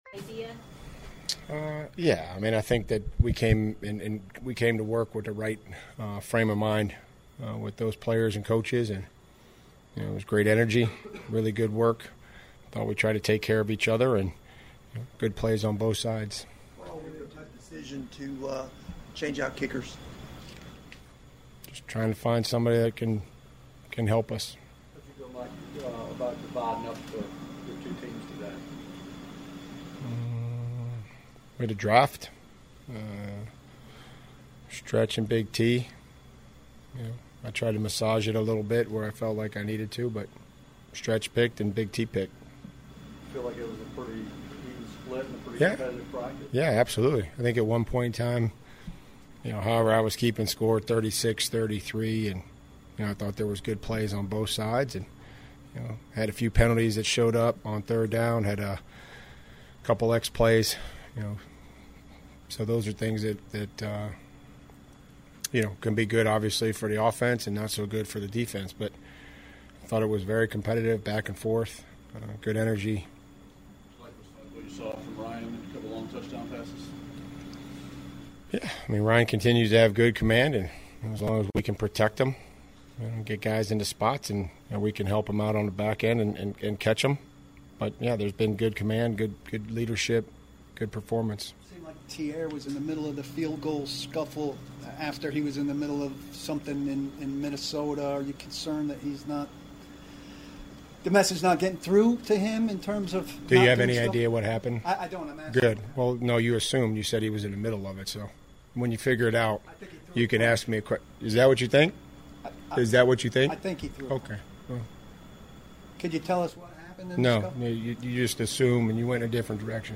To end the hour, ESPN's Tim Hasselbeck joins the show and talks about the Titans issues and the QB's situations. Plus, could Corey Davis make a return to Tennessee?